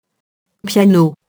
piano [pjano]